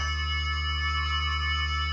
CBHQ_CFO_magnet_loop.ogg